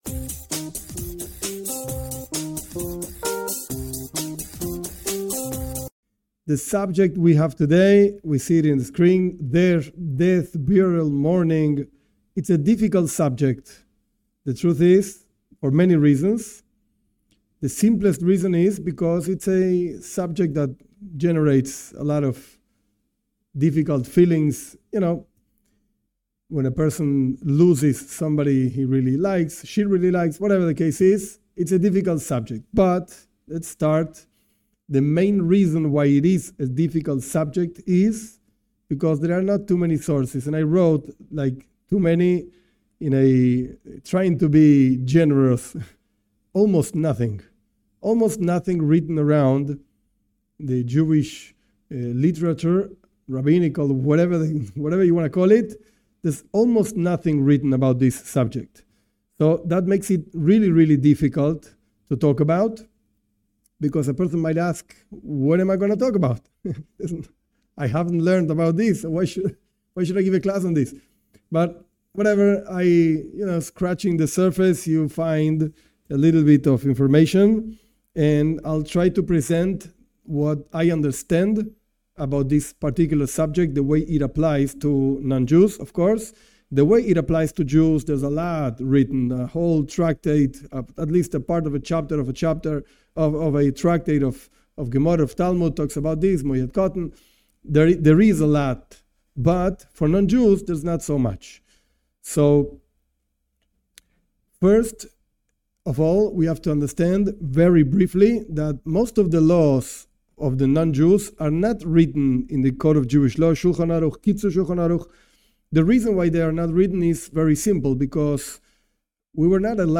This class presents different sources and ideas of the perspective of Judaism for non-Jews in regards to visiting the sick, death, burial and mourning. It is an halachic (legal) class which touches on subjects as burial ceremony, cremation and resurrection of the dead.